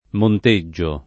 [ mont %JJ o ]